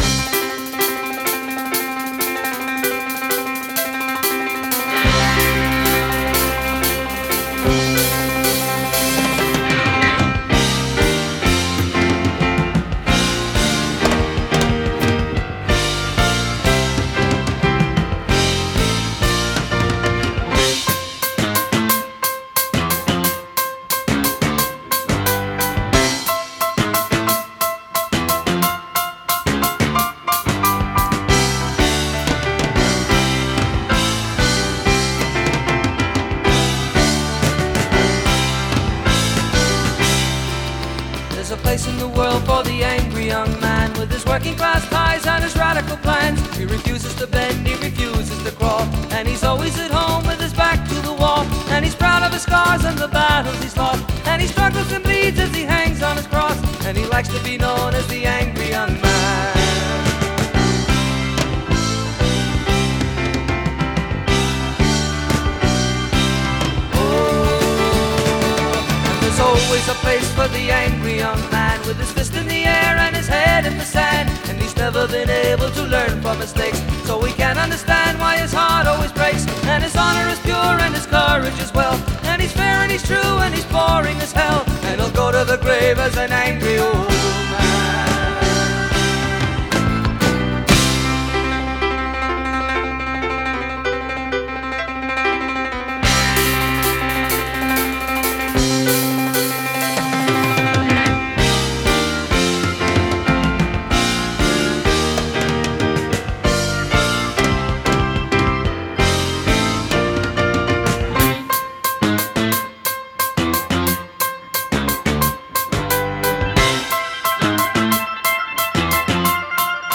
BPM177-192